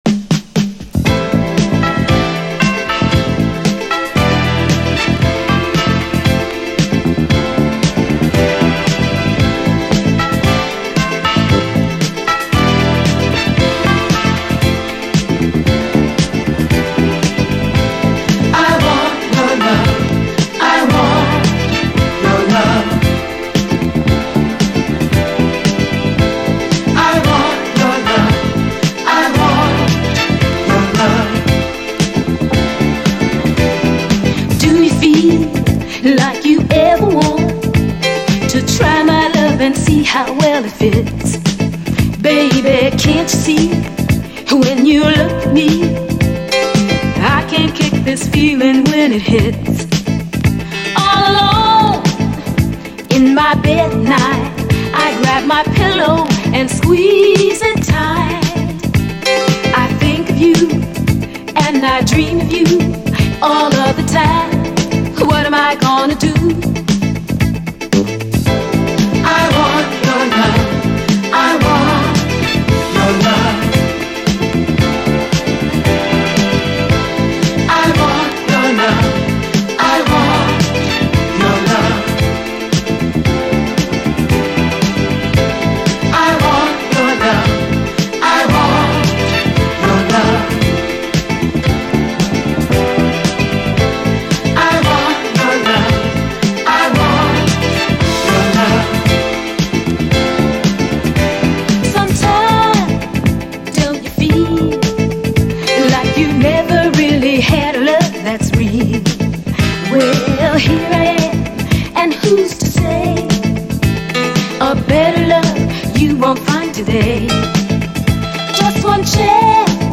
SOUL, 70's～ SOUL, DISCO
永久不滅のガラージ〜ディスコ・クラシック！
エレガントで艶っぽいムードに痺れる大名曲！後半のストリングス〜ブラス・パートもめちゃくちゃカッコいいです。